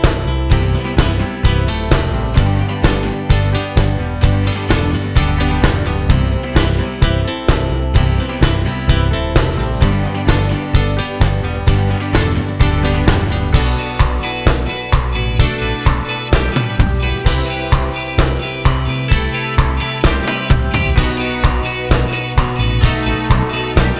For Duet Pop (1980s) 4:14 Buy £1.50